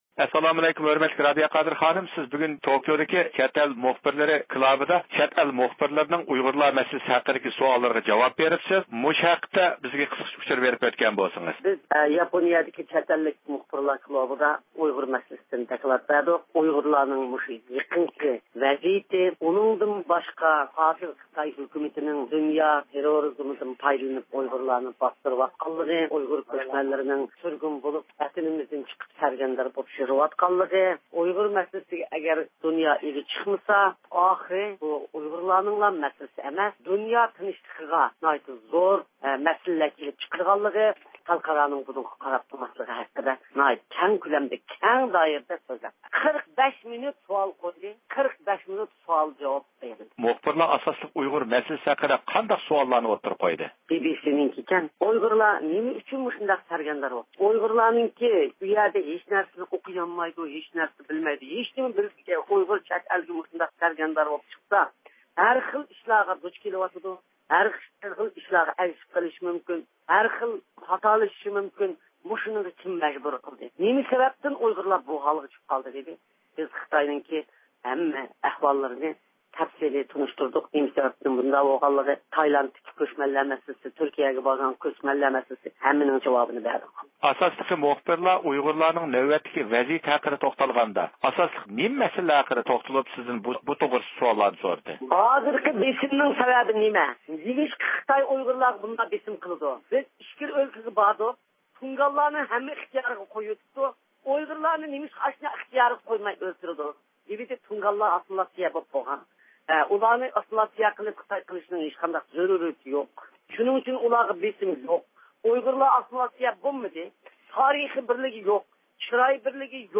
بىز مەزكۇر ئاخبارات ئېلان قىلىش يىغىنى ھەققىدە تولۇق مەلۇماتقا ئېرىشىش ئۈچۈن دۇنيا ئۇيغۇر قۇرۇلتىيىنىڭ رەئىسى رابىيە قادىر خانىم بىلەن تېلېفون سۆھبىتى ئېلىپ باردۇق.